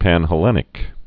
(pănhə-lĕnĭk)